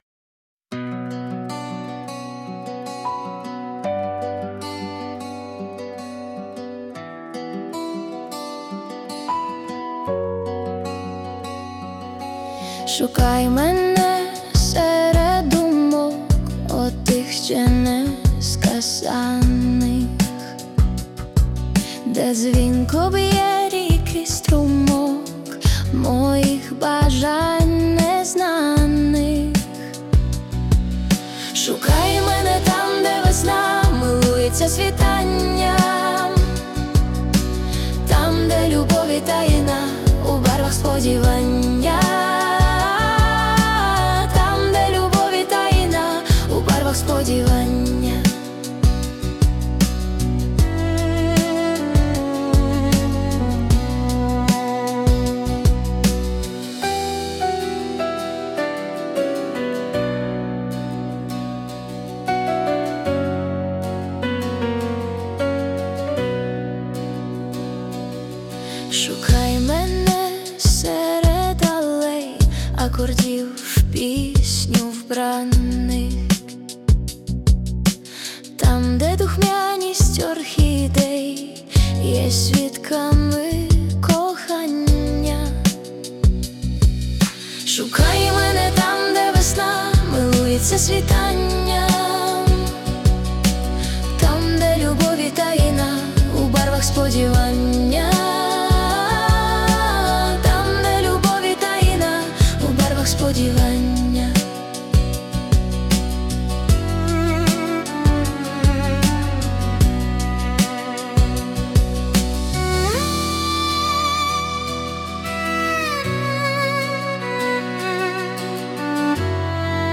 ШУКАЙ МЕНЕ (Пісня)